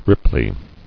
[rip·ply]